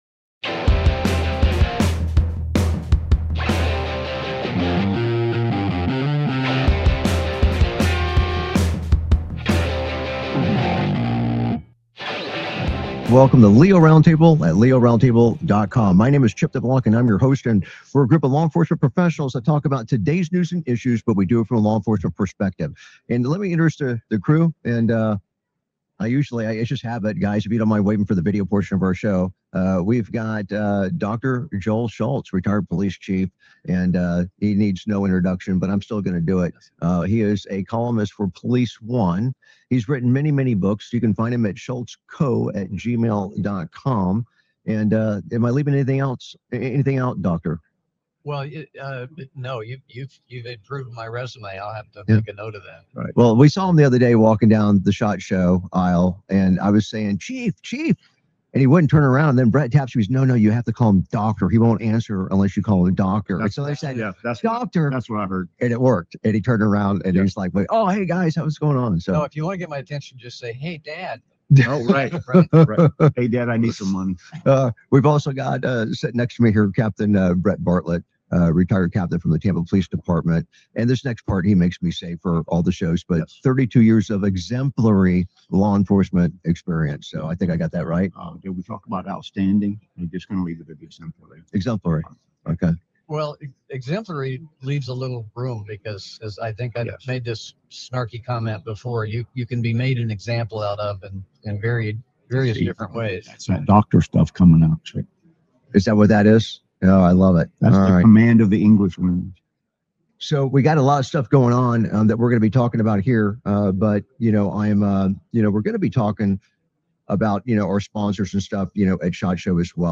Talk Show Episode, Audio Podcast, LEO Round Table and S11E014